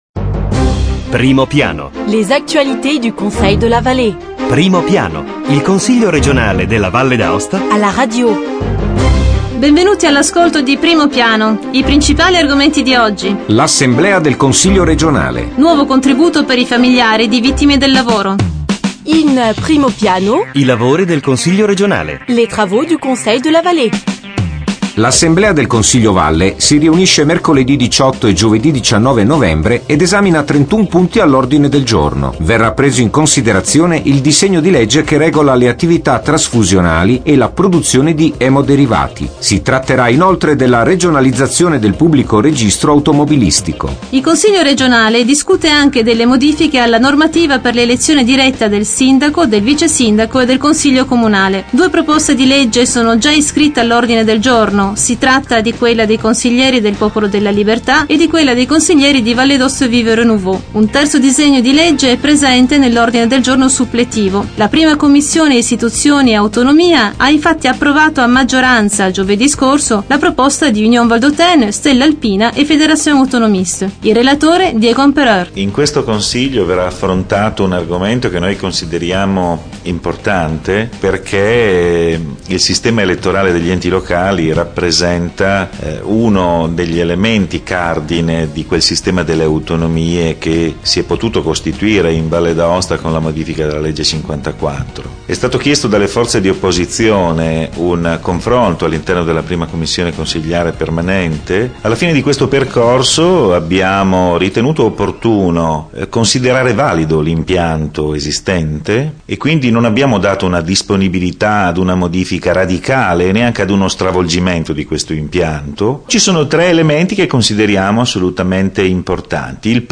Eventi e ricorrenze Documenti allegati Dal 17 novembre 2009 al 23 novembre 2009 Primo Piano Il Consiglio regionale alla radio: approfondimento settimanale sull'attivit� politica, istituzionale e culturale dell'Assemblea legislativa.
interviste con i Consiglieri Diego Empereur e Gianni Rigo